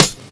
Snares
WTY_SNR.wav